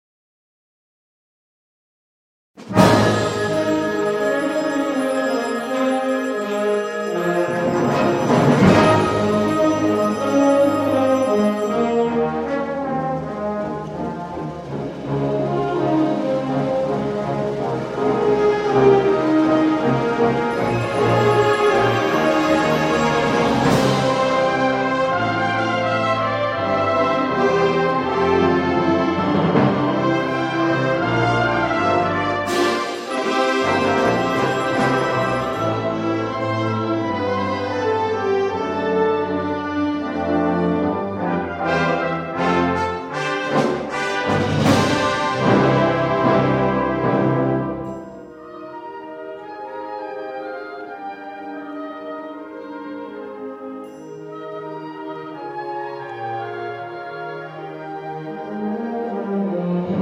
Tuba